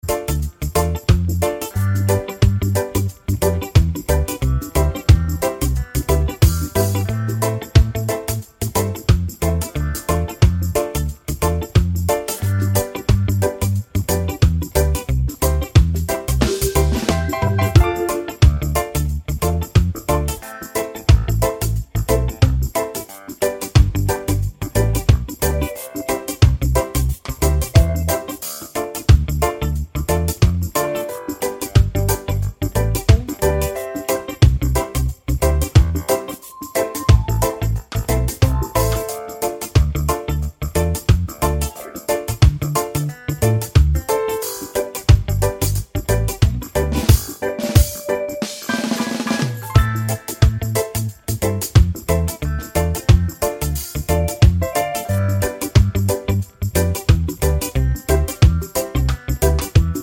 Full Length with No Backing Vocals Reggae 5:10 Buy £1.50